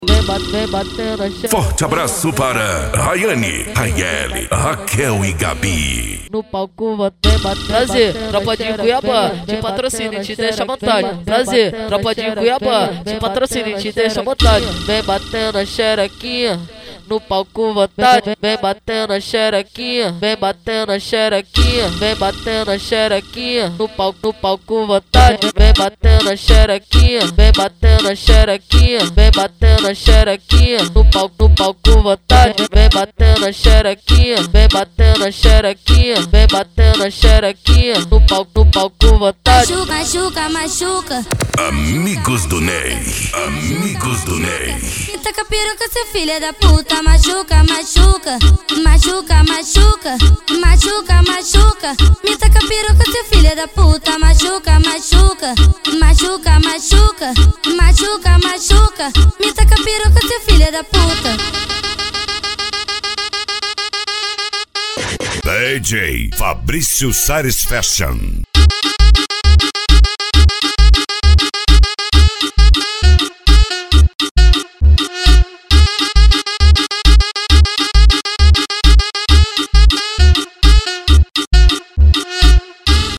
Funk
SERTANEJO